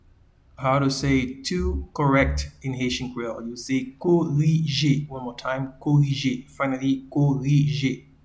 Pronunciation and Transcript:
to-Correct-in-Haitian-Creole-Korije.mp3